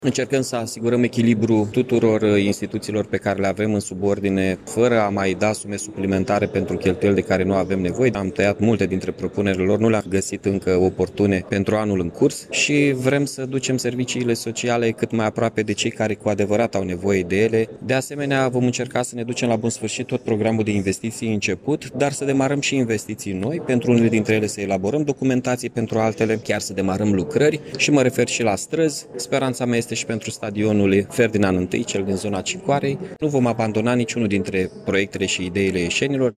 Primarul Mihai Chirica a precizat că, ținând cont de contextul economic actual, au fost diminuate multe capitole bugetare.